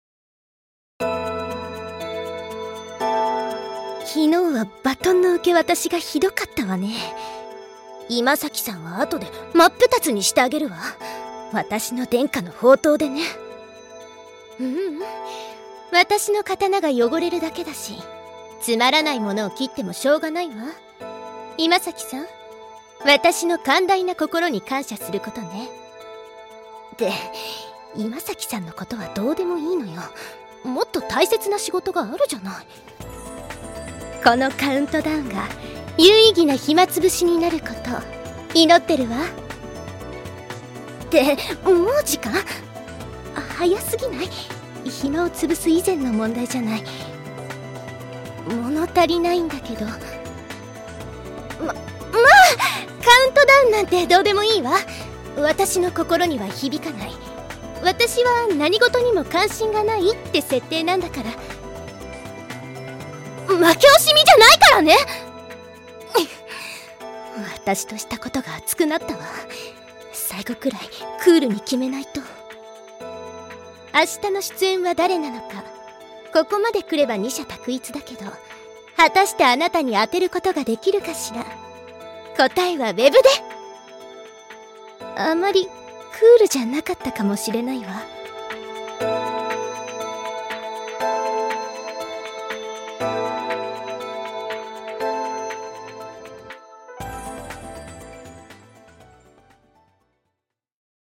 『アストラエアの白き永遠』 発売3日前カウントダウンボイス(琴里)を公開